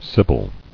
[sib·yl]